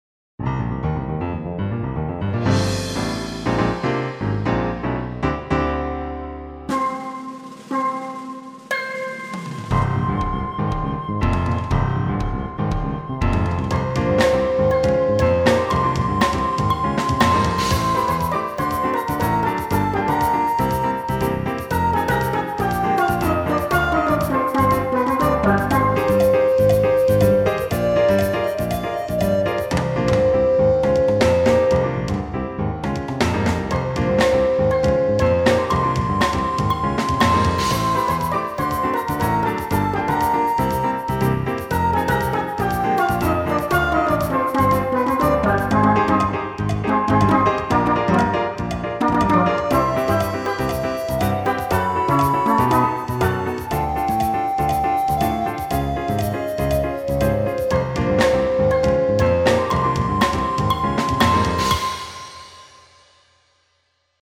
Yeah, compo 9 has this jazzy harmony I totally like. Instrumentation is also perfect, only the ending is a bit bland.